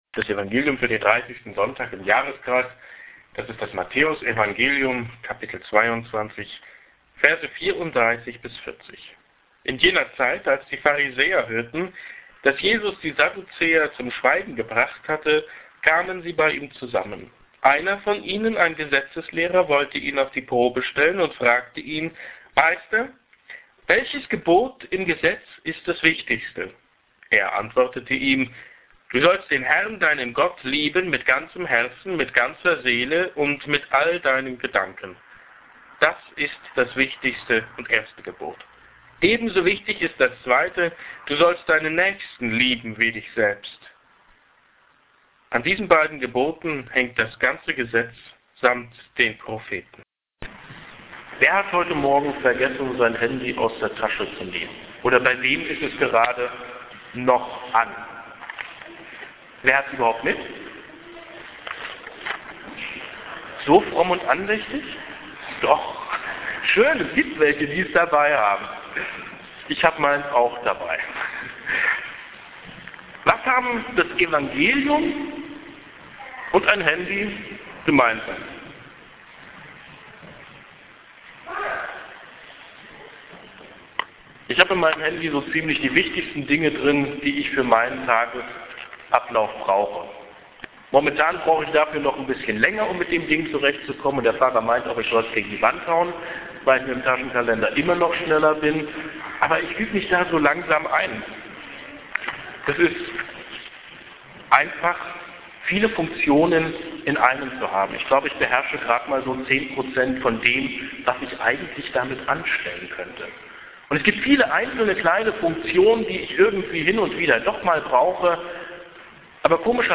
hier-klickt-die-preidigt.mp3